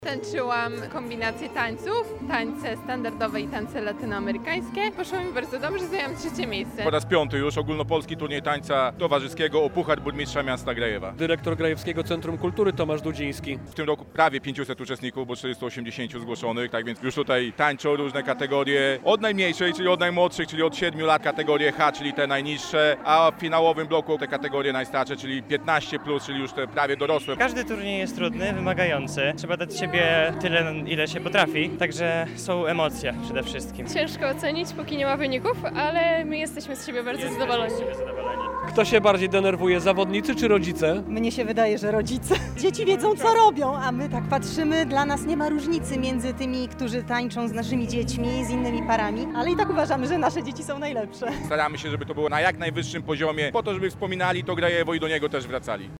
Turniej Tańca Towarzyskiego w Grajewie - relacja
Rywalizacja w sali Miejskiego Ośrodka Sportu i Rekreacji w Grajewie potrwa do 20:00.